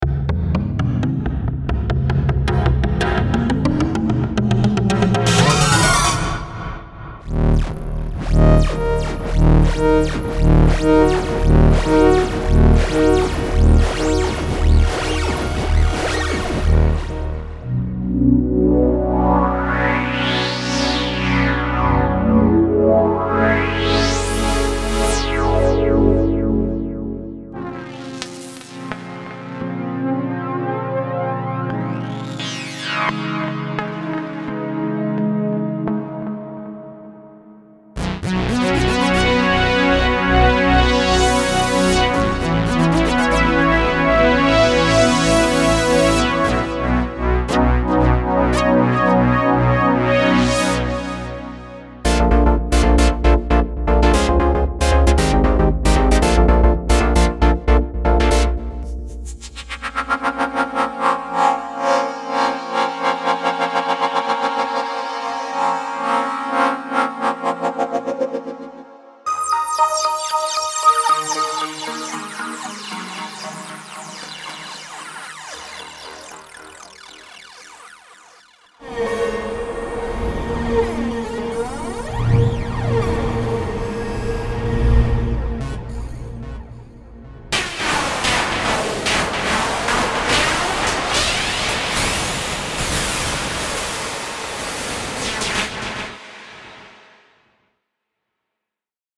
Without drums